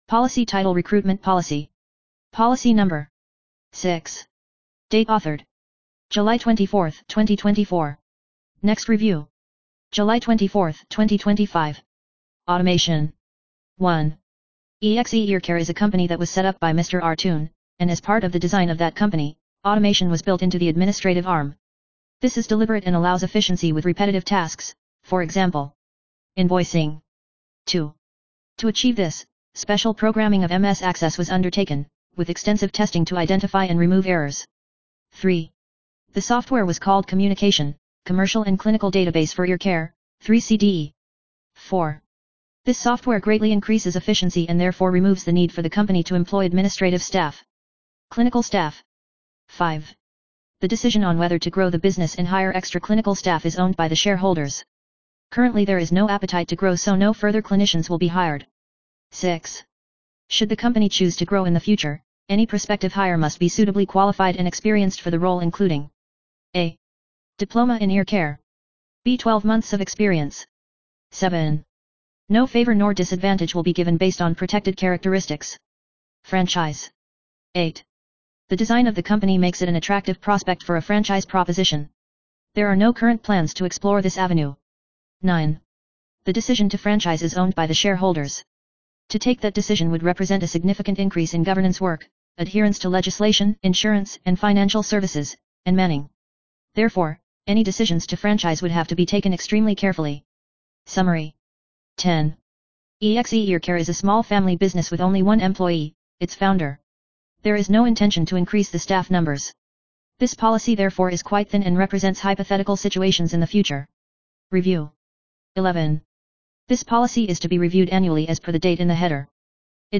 Narration of Recruitment Policy